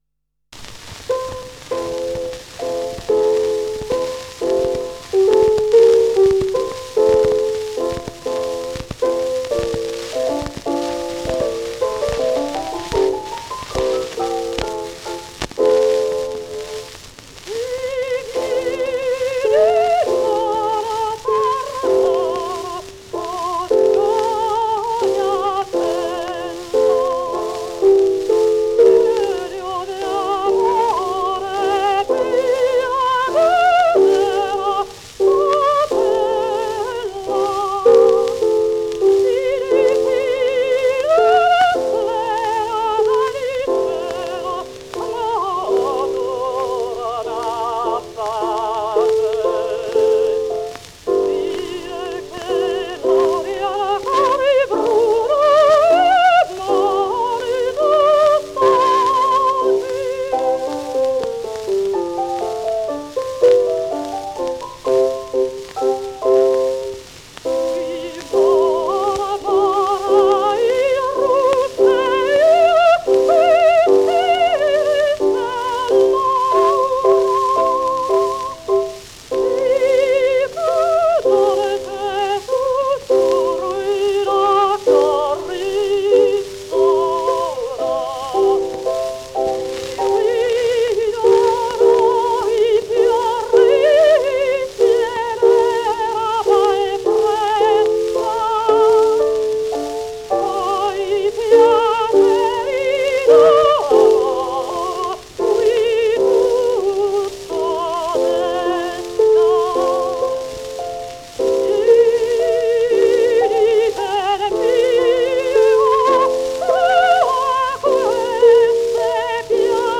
Анжелика Пандольфини (Angelica Pandolfini) (Сполето, 21 августа 1871 - Ленно, 15 июля 1959) - итальянская певица (сопрано).